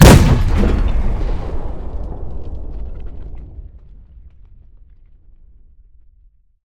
tank-cannon-3.ogg